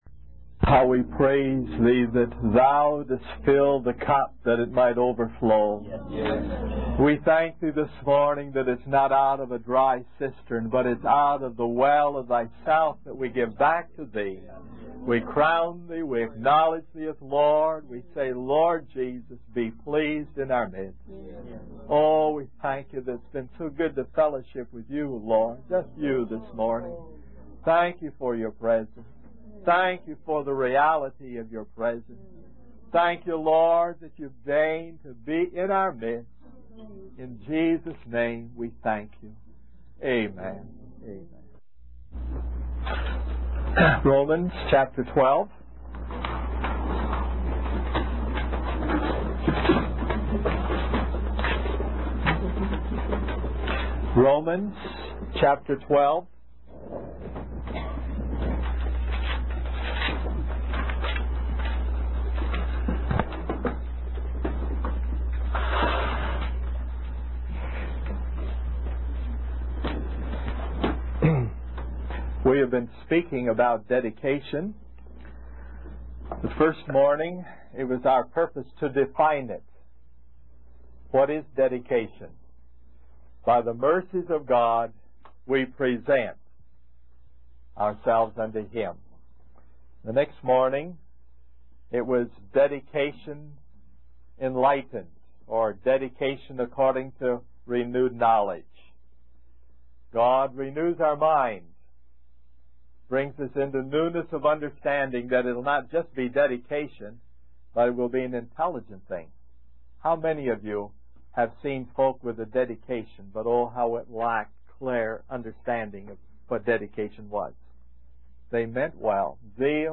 In this sermon, the speaker emphasizes the importance of learning from past experiences and coming to the knowledge of the truth. He refers to the Israelites' 40-year journey in the wilderness as an example of how God tests and develops individuals. The speaker highlights the significance of having a burden and the enablement that comes with it, leading to the development of gifts and ministries.